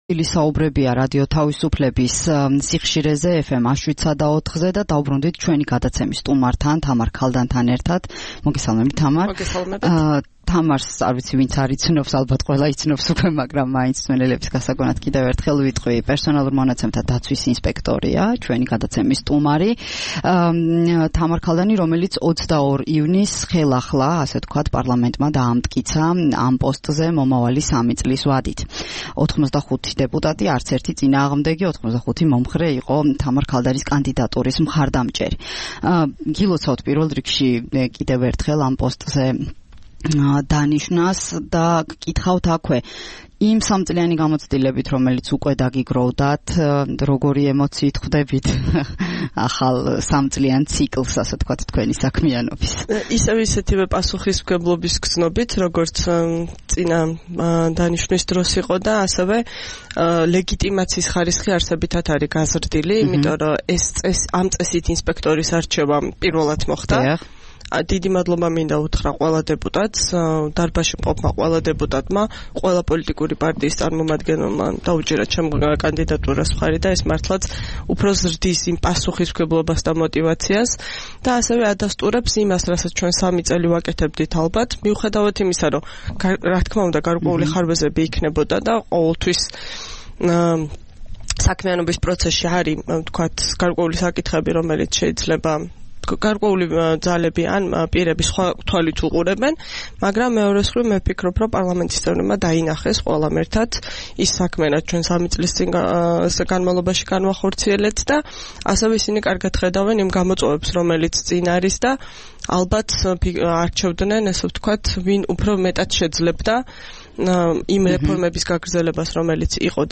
27 ივლისს რადიო თავისუფლების „დილის საუბრების” სტუმარი იყო პერსონალურ მონაცემთა დაცვის ინსპექტორი თამარ ქალდანი. მან ილაპარაკა სამომავლო გეგმებზე. 2 ივლისიდან ის ხელმეორედ დაიკავებს პერსონალურ მონაცემთა დაცვის ინსპექტორის თანამდებობას.